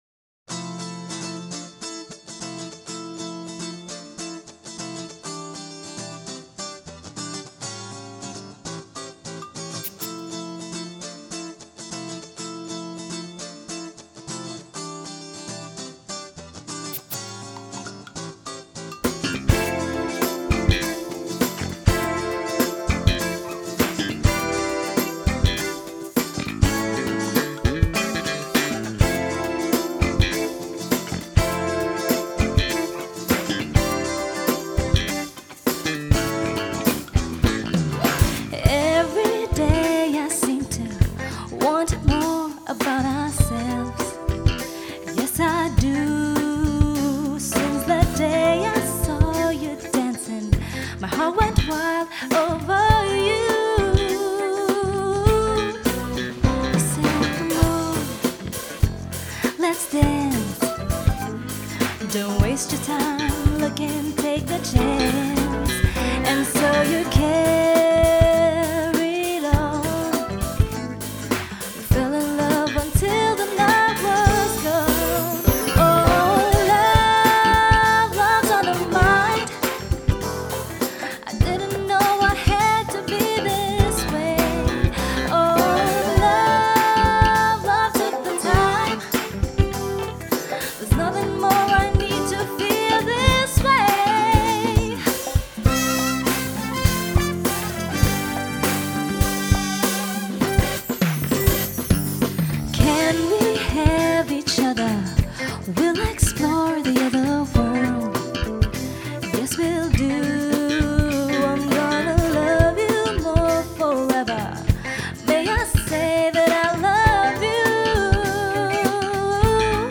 80’Sを思わせるギターカッティングに、シンセや太いベースが入るフロアを意識したキラーな仕上がり！
ジャンル(スタイル) JAPANESE POP / CITY POP